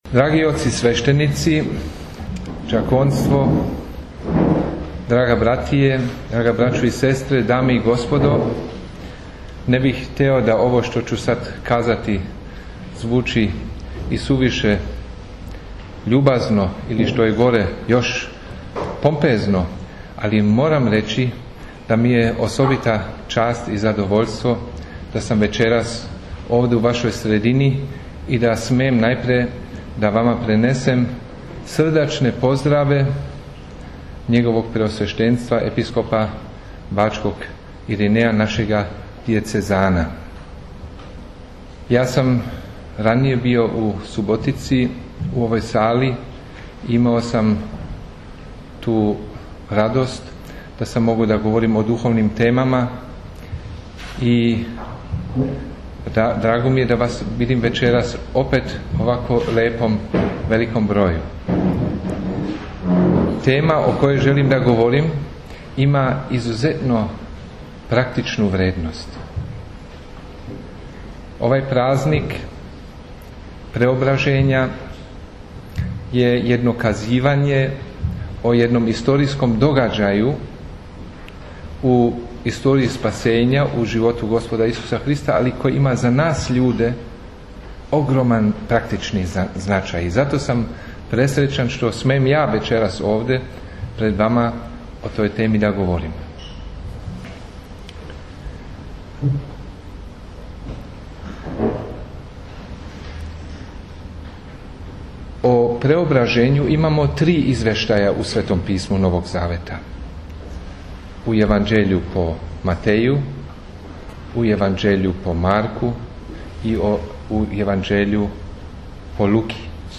На радост присутног верног народа, бденију је присуствовао и архимандрит Андреј Ћилерџић, новоизабрани Епископ ремезијански, који је исте вечери, у просторијама СКЦ Свети Сава беседио о значају празника Преображења Господњег.